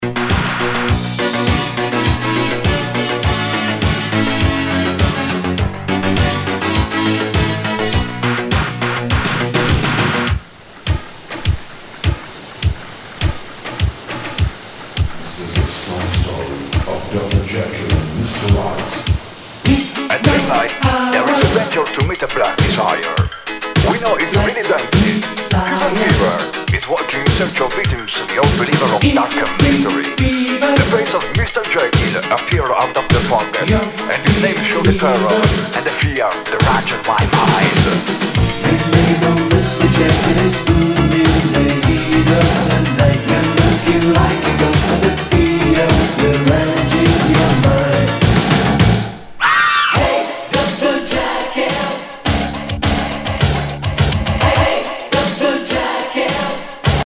SOUL/FUNK/DISCO
ナイス！イタロ / シンセ・ポップ・ディスコ・DJミックス！！！